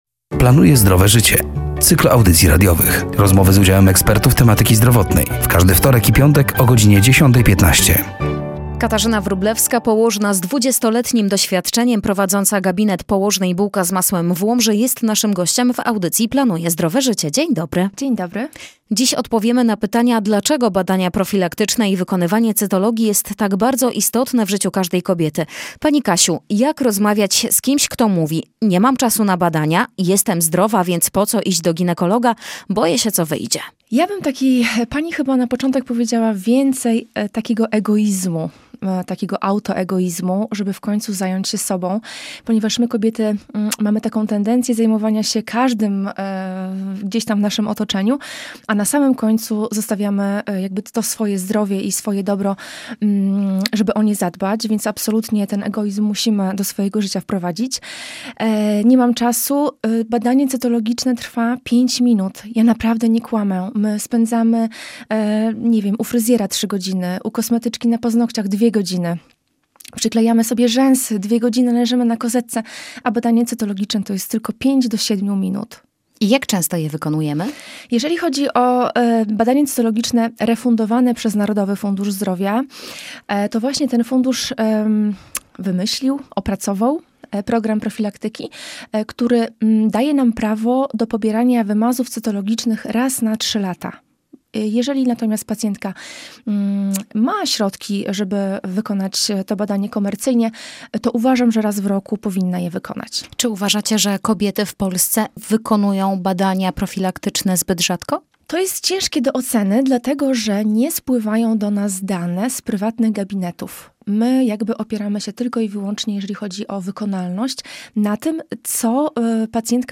“Planuję Zdrowe Życie”, to cykl audycji radiowych poświęconych upowszechnianiu wiedzy z zakresu zdrowego stylu życia, promujących zdrowie i edukację zdrowotną. Rozmowy z udziałem ekspertów tematyki zdrowotnej.